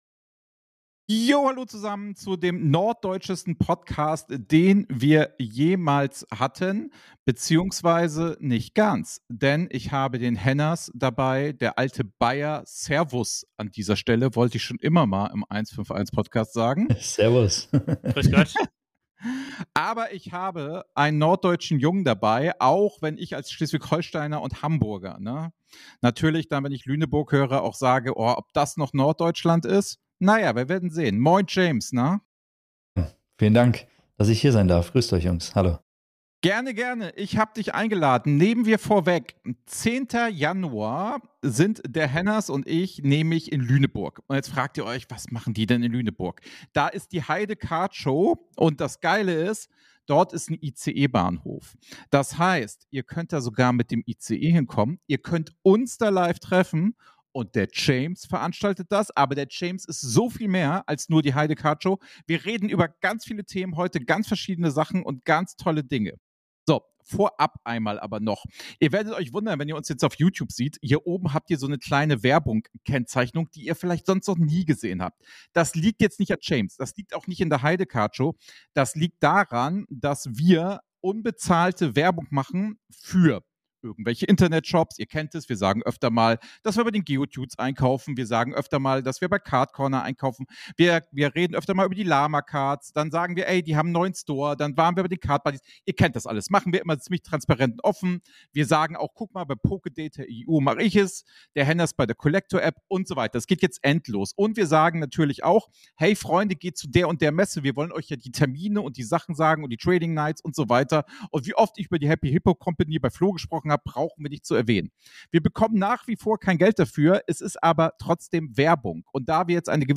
Heidecard Show 2025 Die neue Pokémon Messe im Norden – Interview